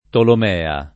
Tolomea [ tolom $ a ]